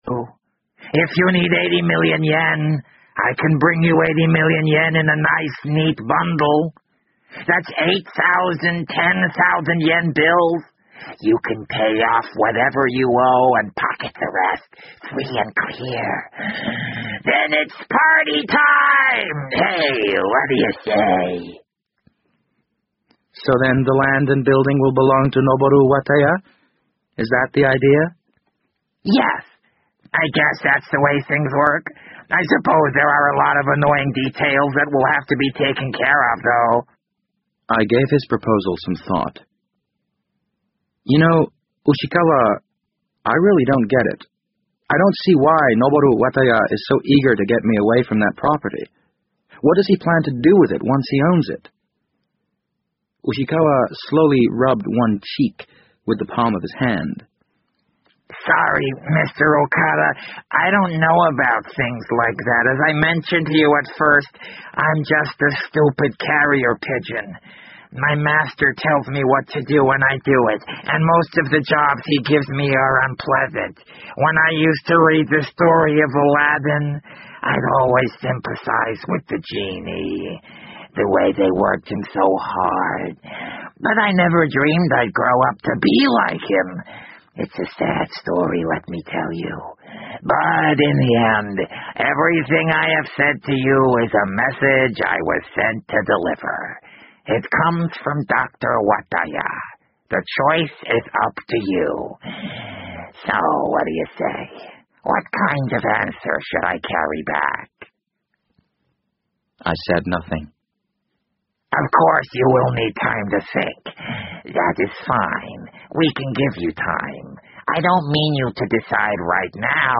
BBC英文广播剧在线听 The Wind Up Bird 012 - 1 听力文件下载—在线英语听力室